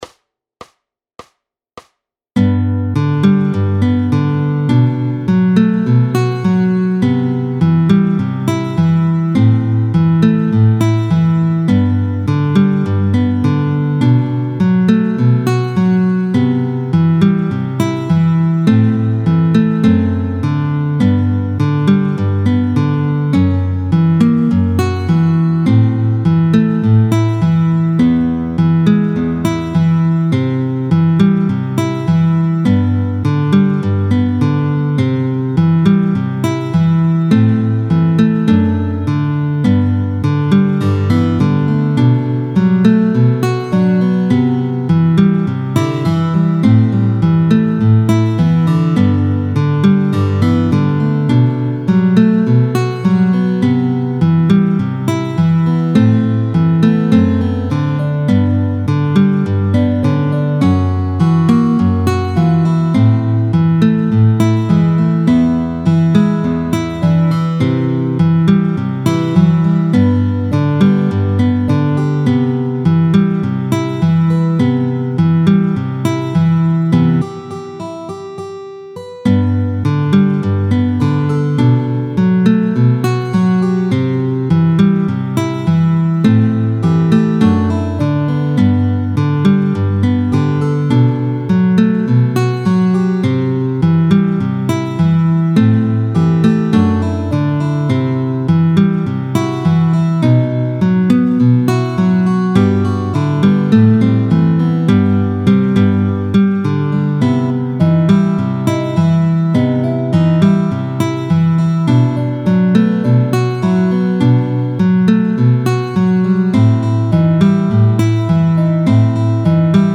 picking.